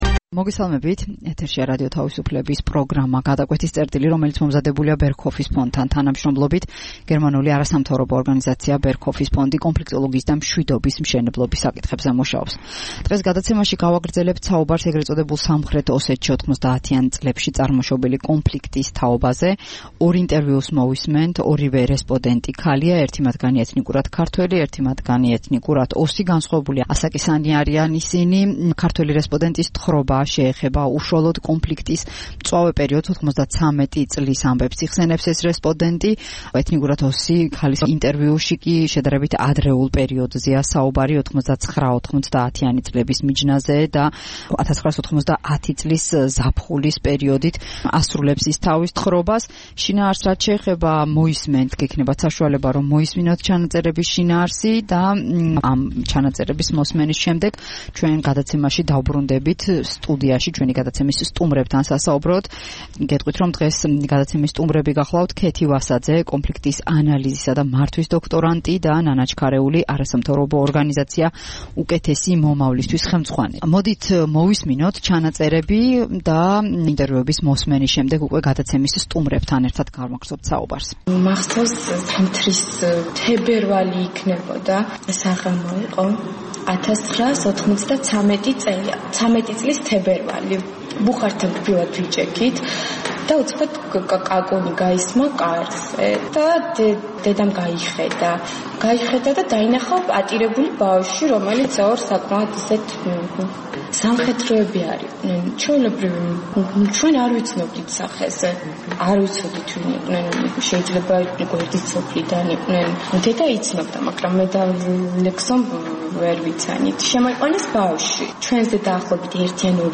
გადაცემაში ორი რესპონდენტი ქალი იხსენებს ე. წ. სამხრეთ ოსეთში 90-იან წლებში მიმდინარე კონფლიქტის სხვადასხვა პერიოდს.